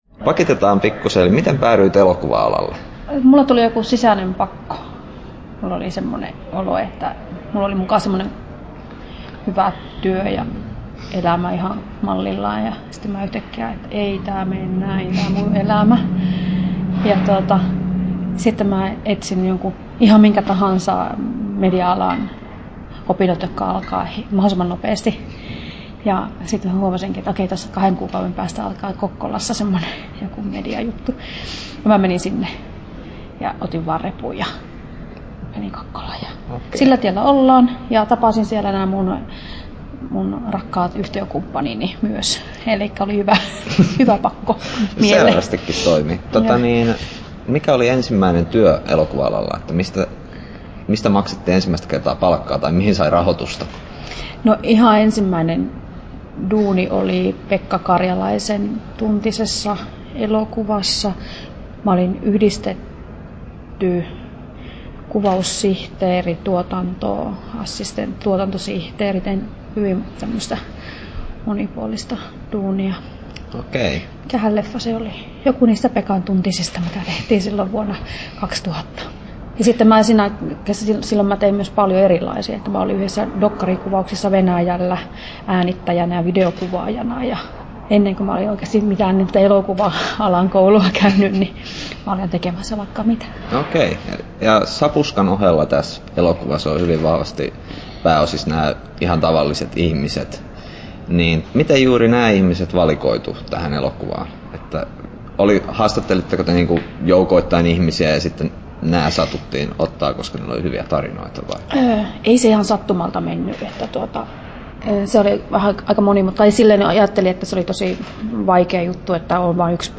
Tallennettu: 23.1.2012, Turku